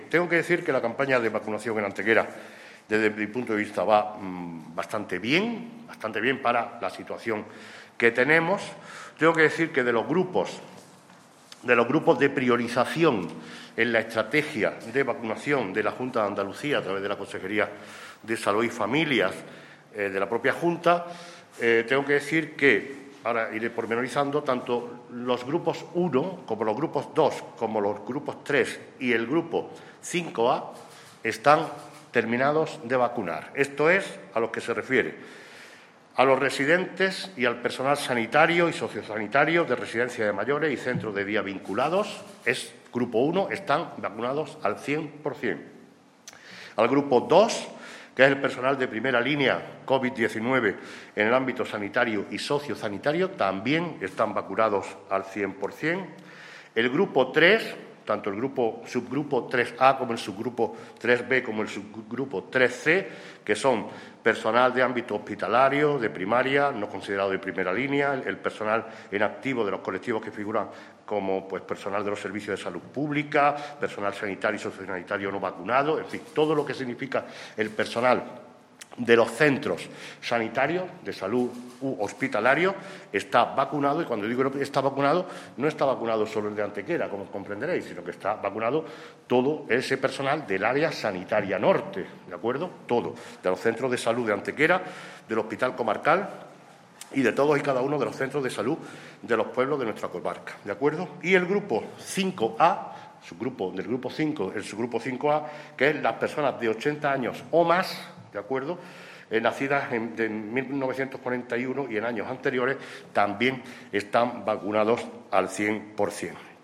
El alcalde de Antequera, Manolo Barón, ha comparecido en la mañana de hoy ante los medios de comunicación para informar a la población sobre cómo se está desarrollando la campaña de vacunación frente a la COVID-19 en nuestro municipio, todo ello tras los contactos directos que se producen constantemente con la gerencia y responsables del Área Sanitaria Norte de Málaga.
Cortes de voz